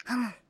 sukasuka-anime-vocal-dataset